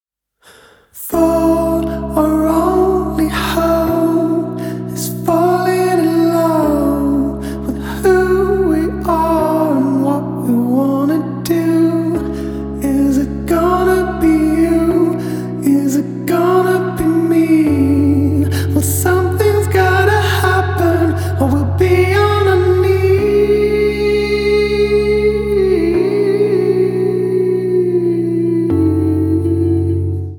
• Качество: 128, Stereo
мужской вокал
спокойные
alternative pop